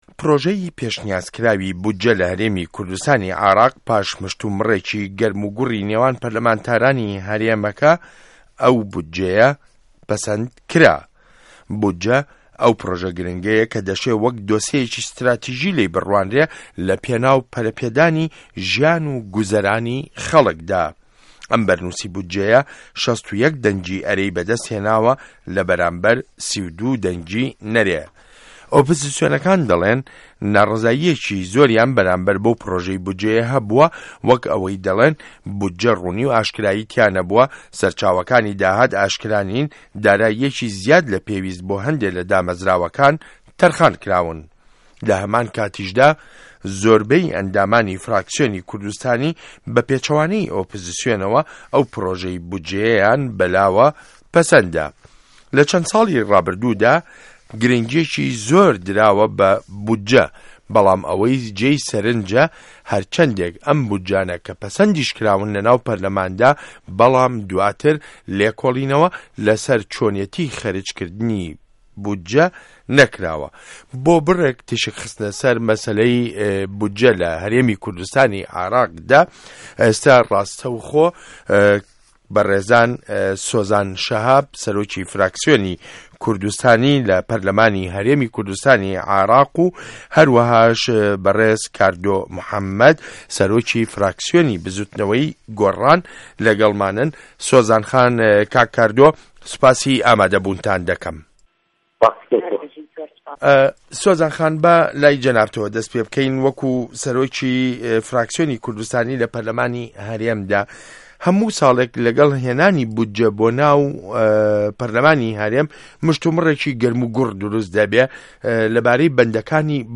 مێزگرد : بودجه‌ی هه‌رێمی کوردستانی عێراق